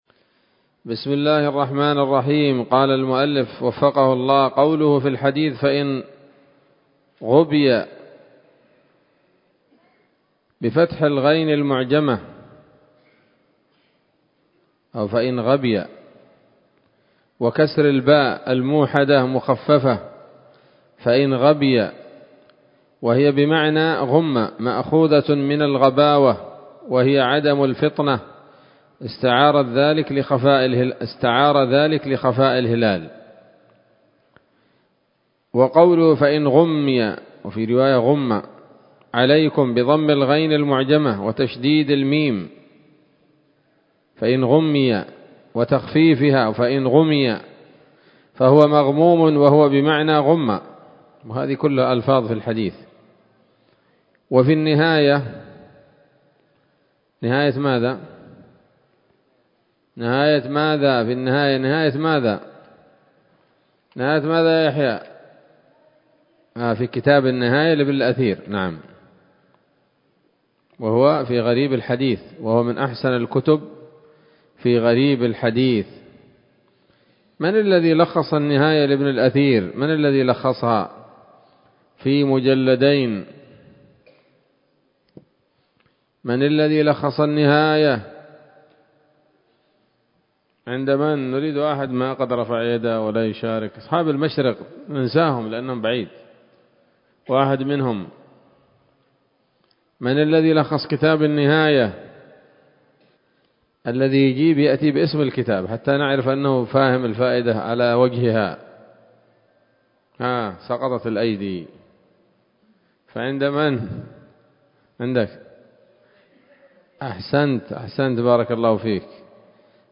الدرس الرابع من كتاب الصيام من نثر الأزهار في ترتيب وتهذيب واختصار نيل الأوطار